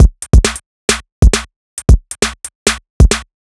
Rollout Break 135.wav